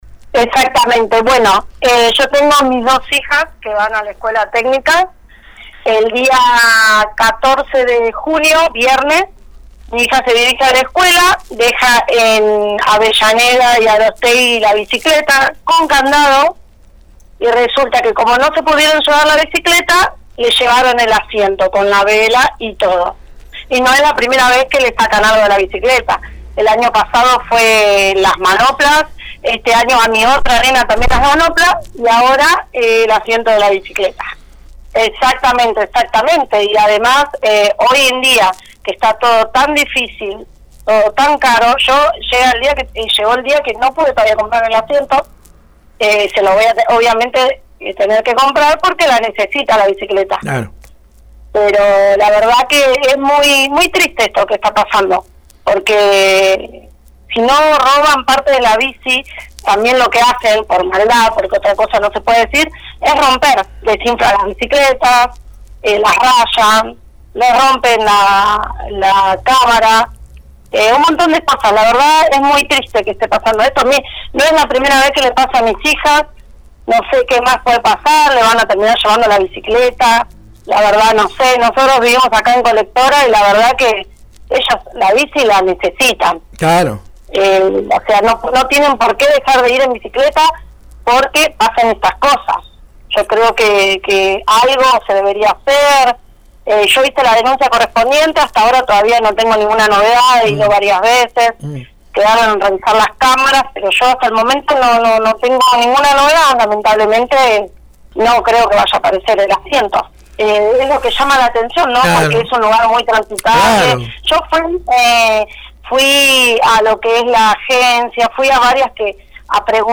La oyente explicó que «hablando con otros chicos nos cuentan que se roban las manoplas, pedales, frenos. Es de no creer».